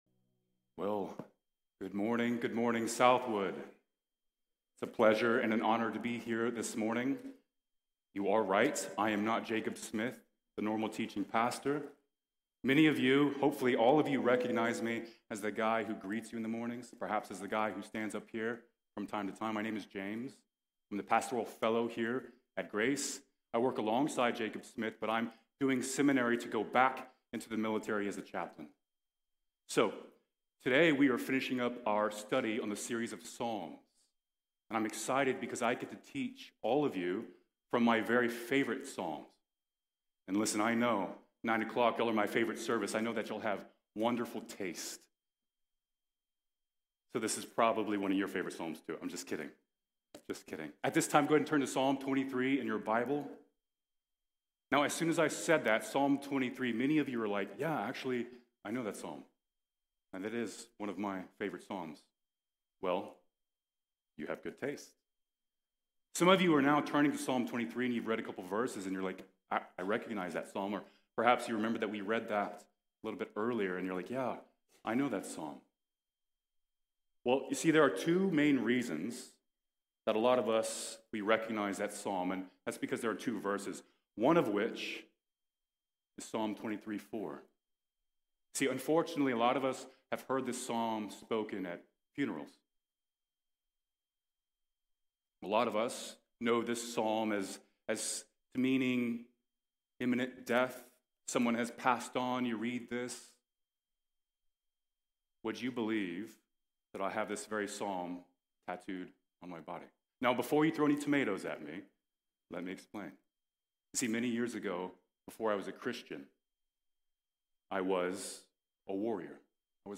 The Lord is My Shepherd | Sermon | Grace Bible Church